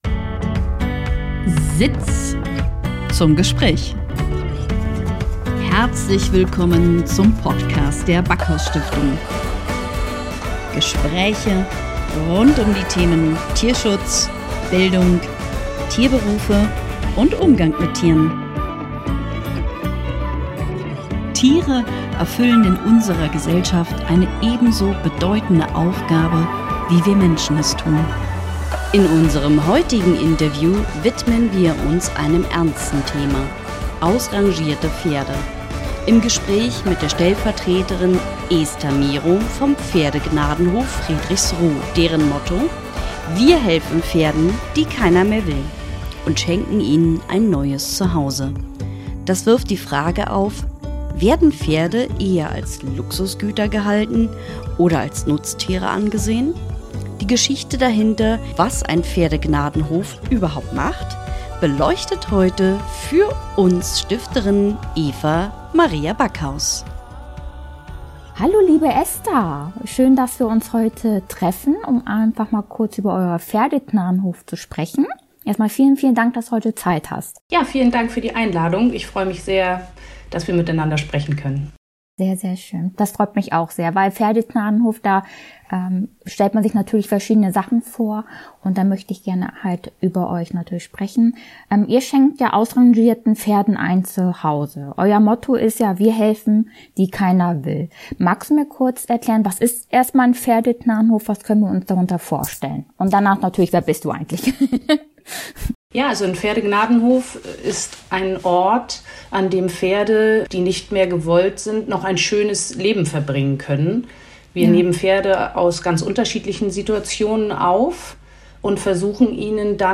In dieser Folge haben wir im Interview: Pferdegnadenhof Friedrichsruh. Sie schenken ausrangierten Pferden ein Zuhause.